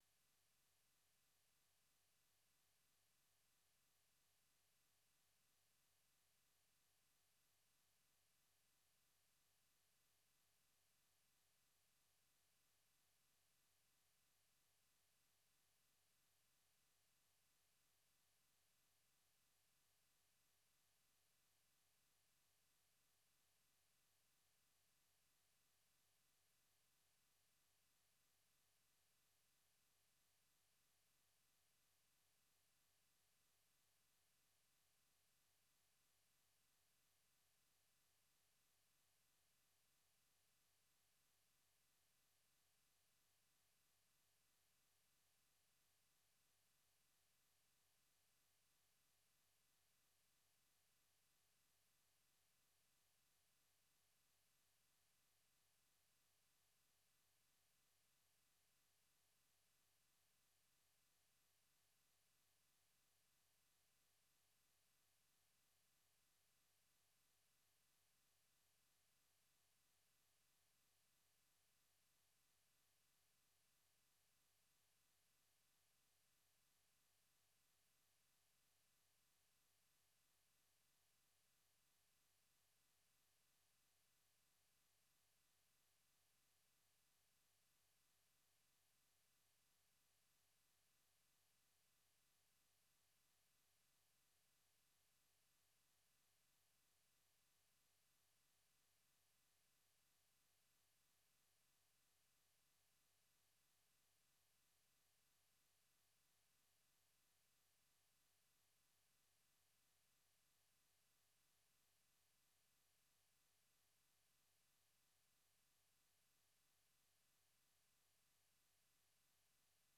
Daybreak Africa is a 30-minute breakfast show looks at the latest developments on the continent and provides in-depth interviews, and reports from VOA correspondents.